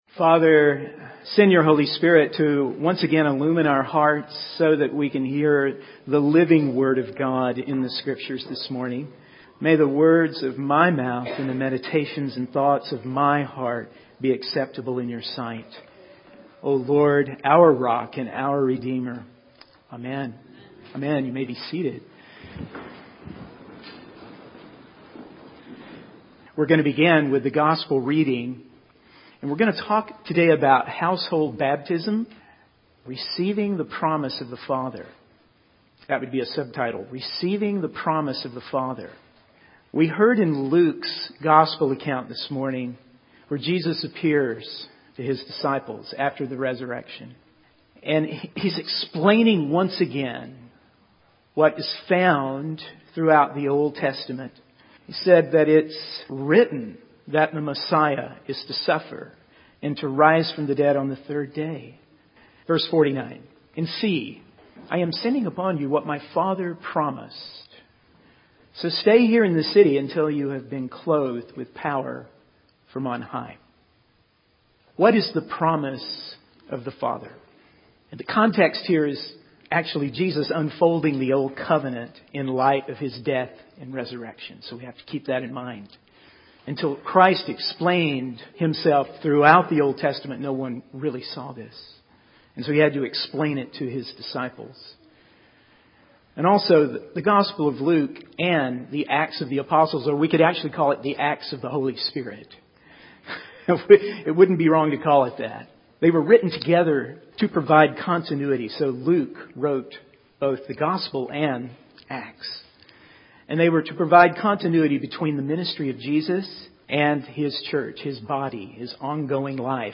In this sermon, the preacher begins by asking the congregation if they believe in God the Father and Jesus Christ the Son of God. He then goes on to talk about the promise of the Father, which is the Kingdom of God, defined as righteousness, peace, and joy in the Holy Spirit.
The sermon concludes with the presentation of a candidate for holy baptism and the congregation renewing their baptismal covenant.